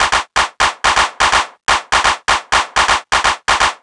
cch_percussion_loop_working_125.wav